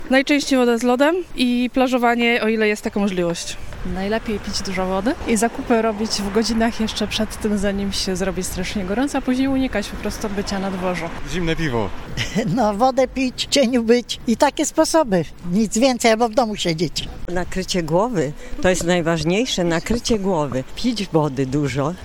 Mieszkańcy Stargardu podzielili się z nami swoimi sposobami na upały.